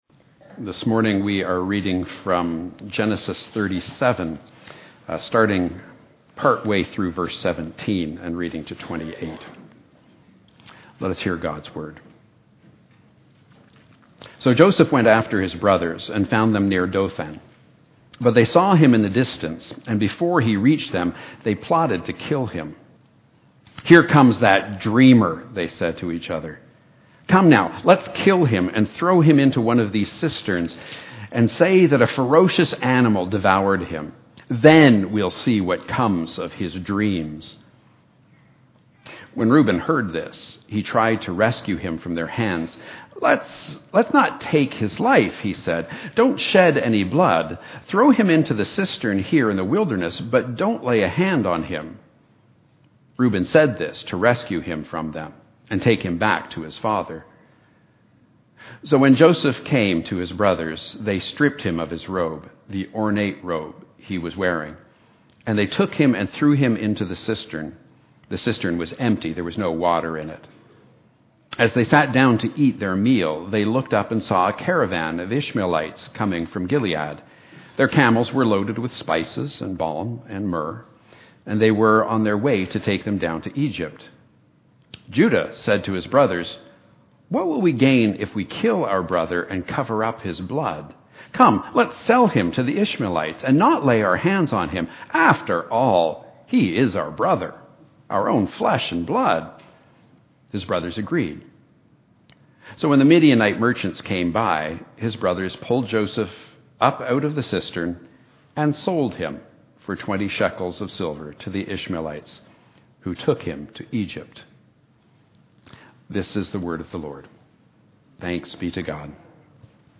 A message from the series "Visioning."